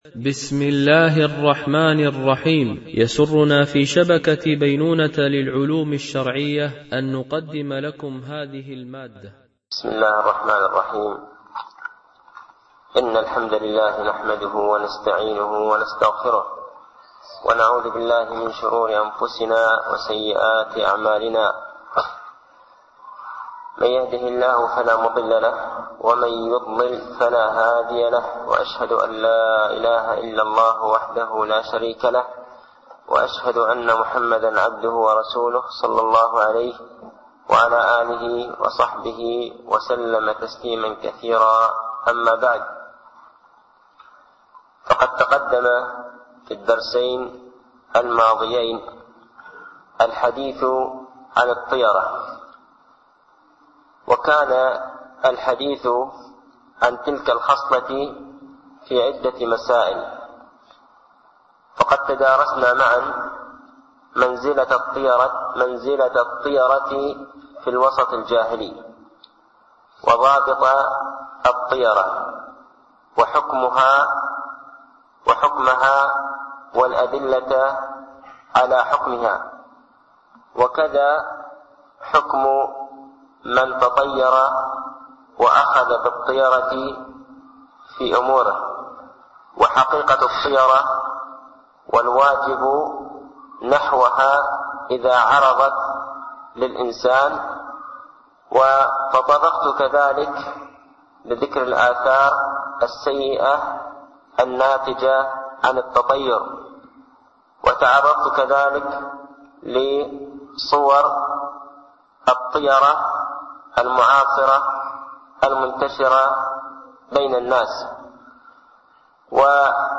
شرح أعلام السنة المنشورة ـ الدرس 110 ( الفأل وأحكامه )